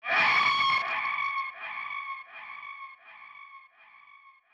Navy Scream.wav